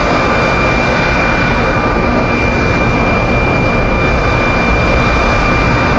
jet-loop.ogg